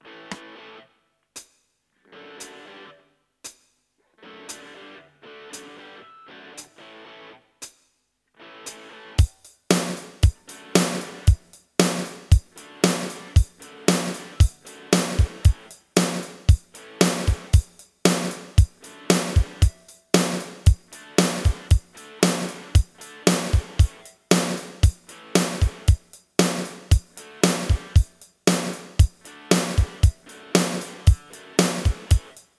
Genre hårdrock/grunge/punk/metal
Typ av gitarr elgitarr
• Stil: Metal